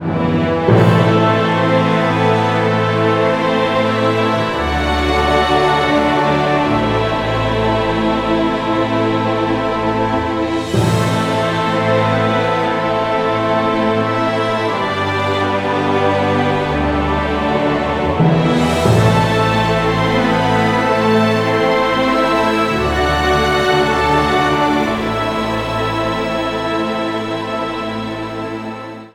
• Качество: 320, Stereo
оркестр
классика